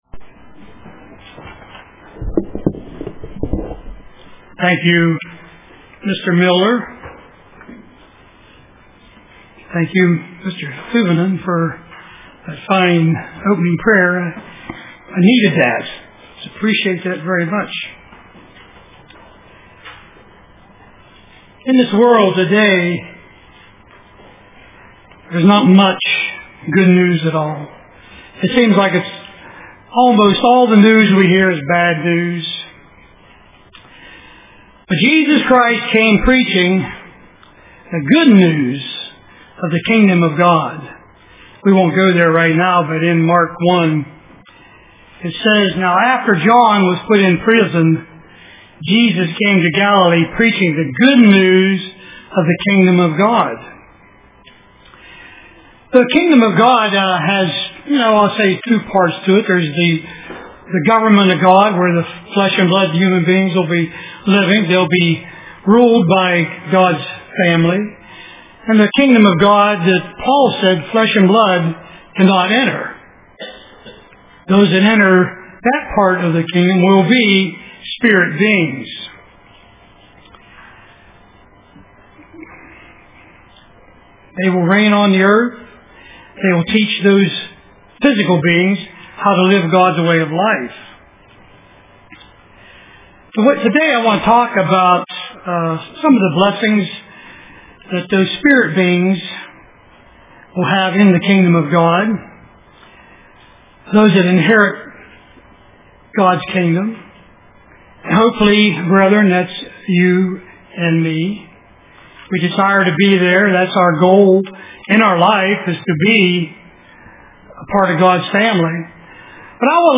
Print You are Worthy UCG Sermon